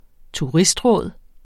Udtale [ -ˌʁɔˀð ]